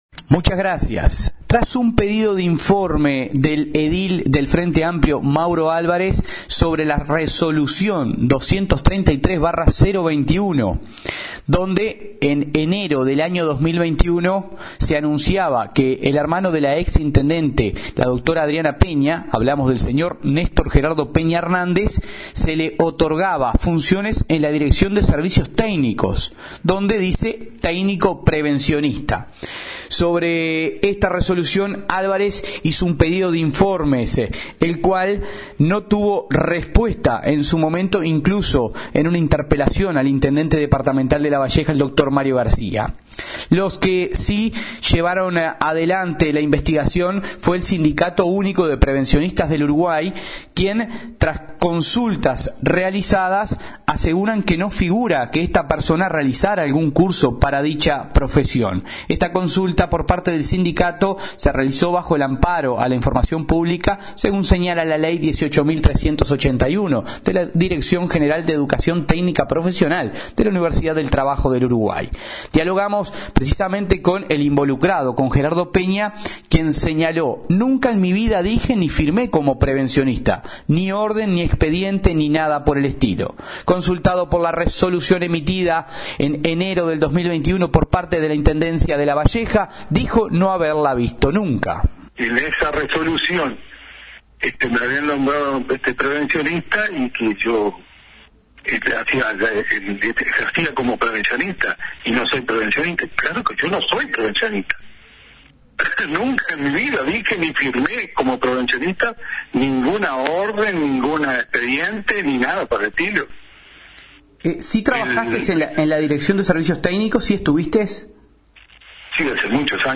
Informe del corresponsal